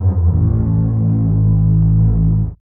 MOAN EL 02.wav